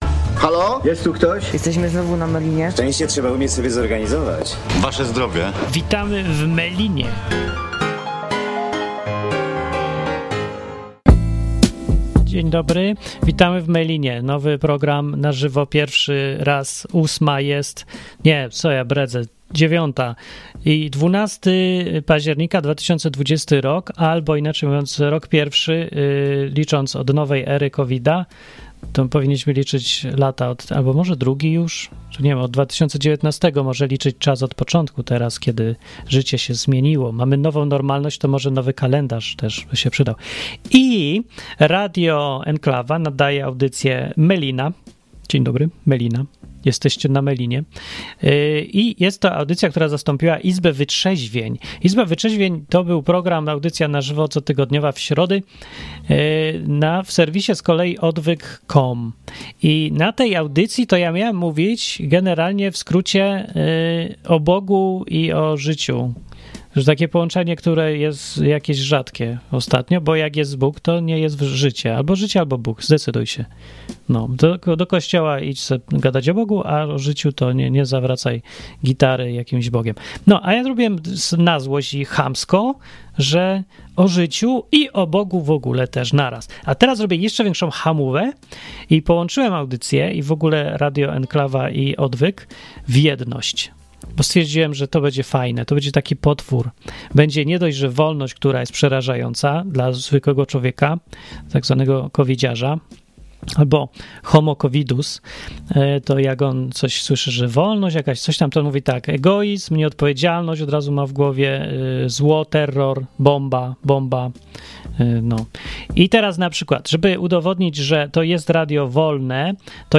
Melina w radiu Enklawa to poniedziałkowa audycja na żywo. Rozmowy ze słuchaczami o wolności, o życiu, o społeczeństwie, o Bogu, o relacjach i o tym co kogo gryzie albo cieszy.